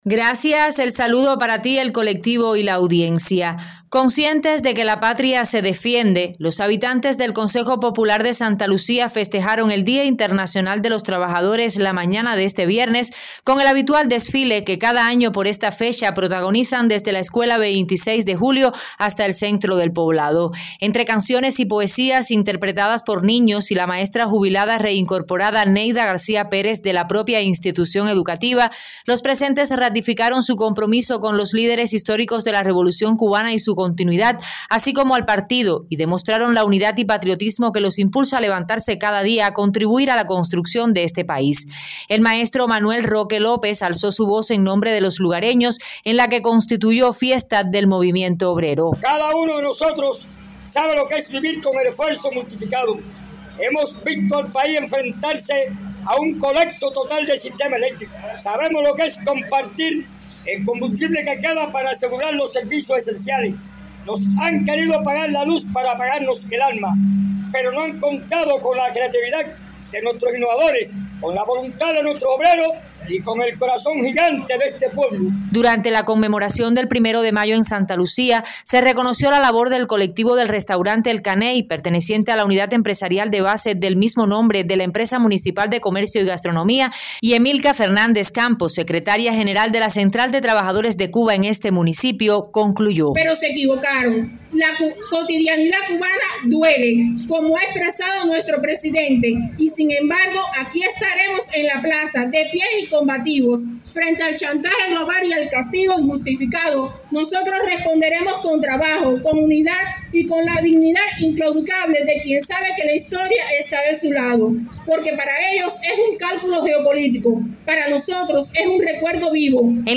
Conscientes de que la patria se defiende, los habitantes del Consejo Popular de Santa Lucía festejaron el Día Internacional de los trabajadores la mañana de este viernes, con el habitual desfile que cada año por esta fecha protagonizan desde la Escuela “26 de Julio” hasta el centro del poblado.